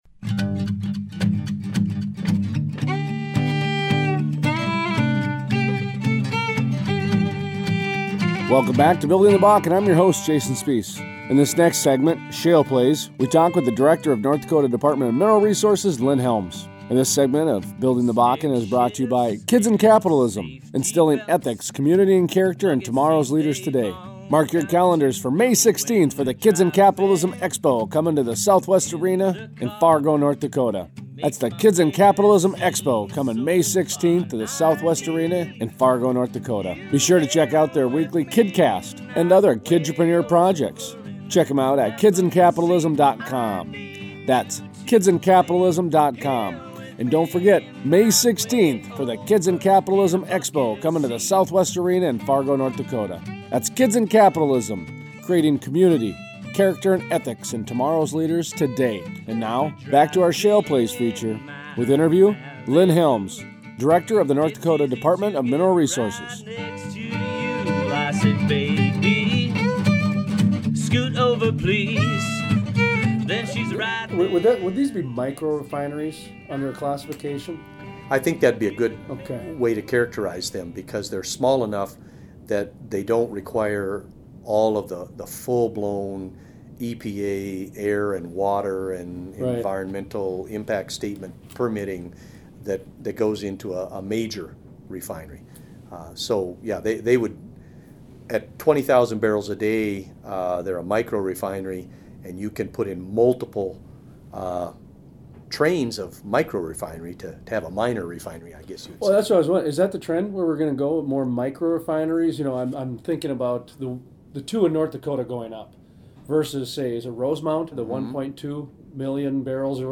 Interveiw: Lynn Helms, director of North Dakota Department of Mineral Resources